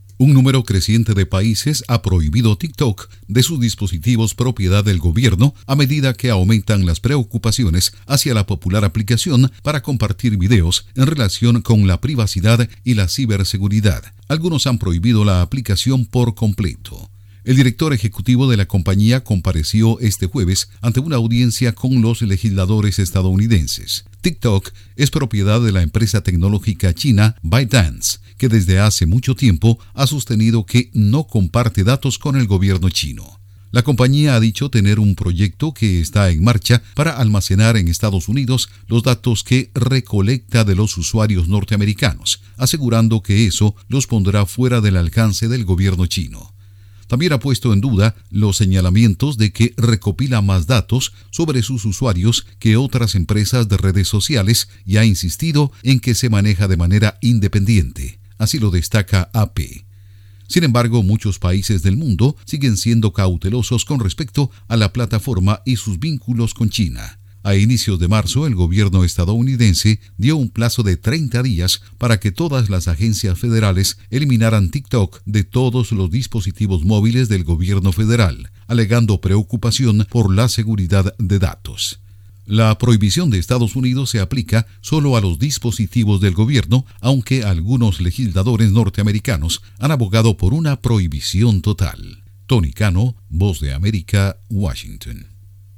Asciende la lista de países que han prohibido TikTok. Informa desde la Voz de América en Washington